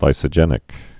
(līsə-jĕnĭk)